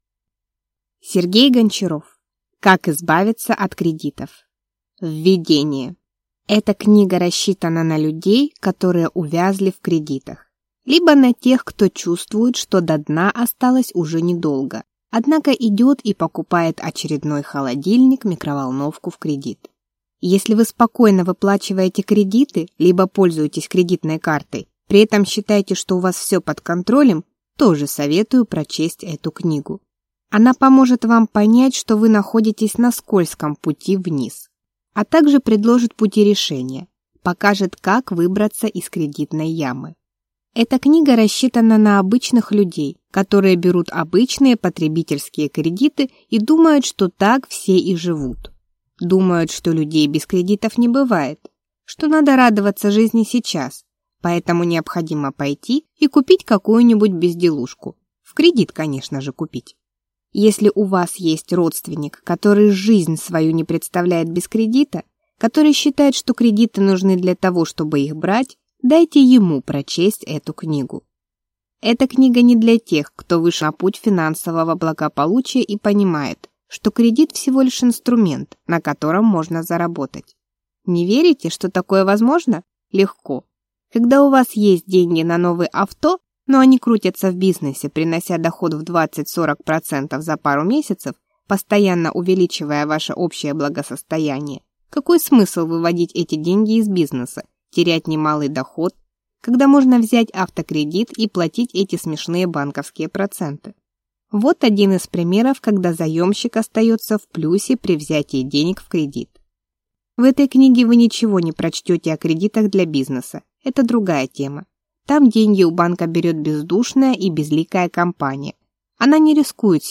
Аудиокнига Как избавиться от кредитов | Библиотека аудиокниг